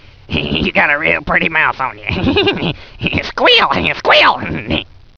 Beavis recites a little poem for you